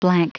Prononciation du mot blank en anglais (fichier audio)
Prononciation du mot : blank